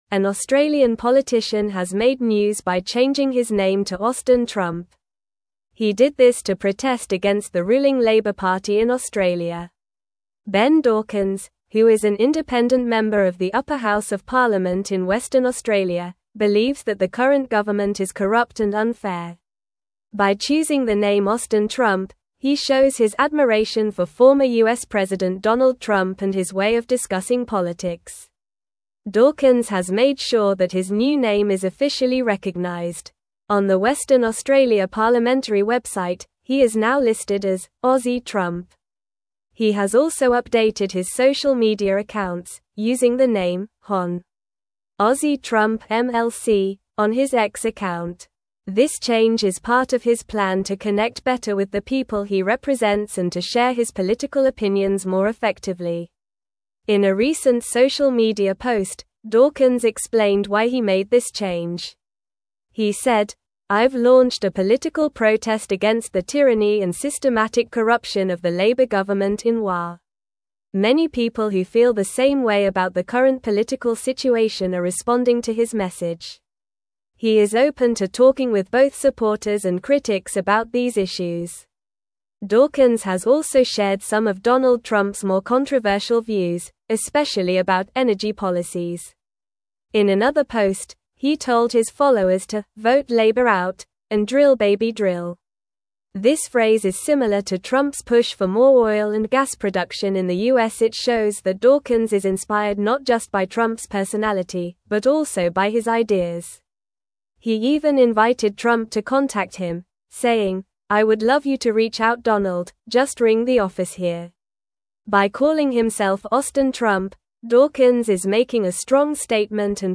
Normal
English-Newsroom-Upper-Intermediate-NORMAL-Reading-Australian-Politician-Changes-Name-to-Austin-Trump.mp3